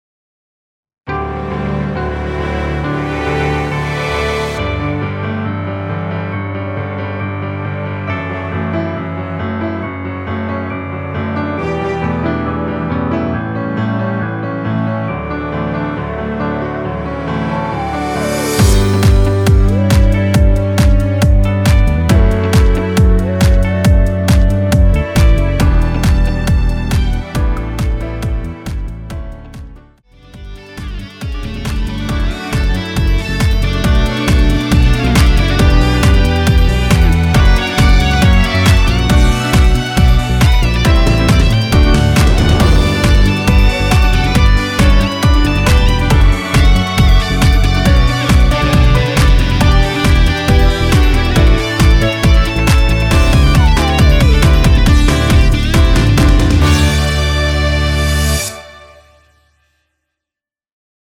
원키에서 (-2) 내린 MR 입니다.
엔딩이 페이드 아웃이라?노래 하시기 좋게 엔딩을 만들어 놓았으니 미리듣기 참조 하세요.
앞부분30초, 뒷부분30초씩 편집해서 올려 드리고 있습니다.
중간에 음이 끈어지고 다시 나오는 이유는